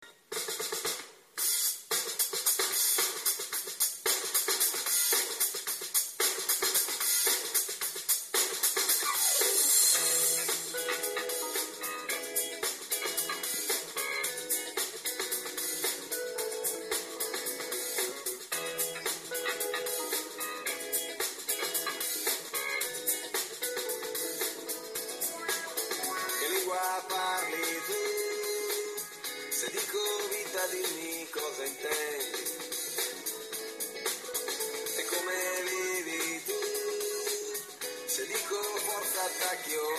Side note: Making the most of this baking ‘me time’, I discovered an Italian online radio station. Very motivational – you’ll be dancing with your mixing bowl guaranteed!
00_baking-music_unknown.mp3